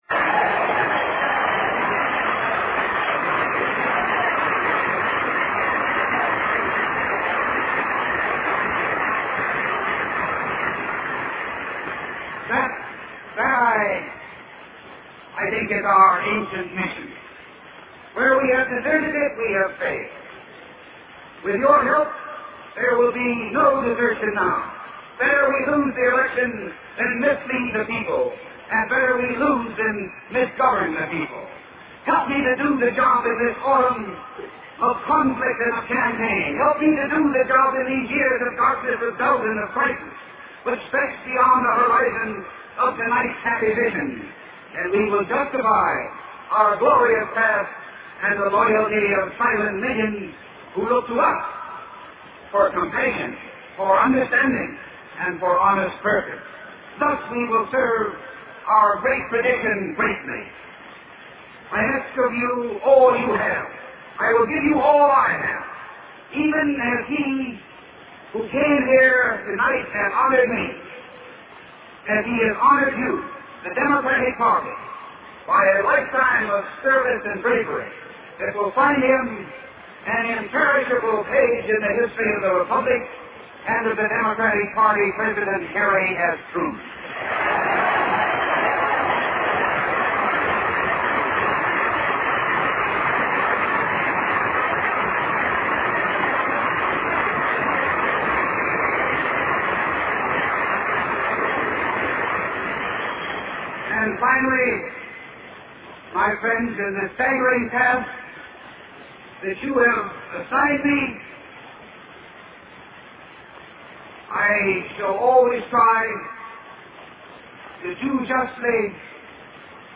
经典名人英语演讲(中英对照):Presidential Nomination Acceptance Speech 10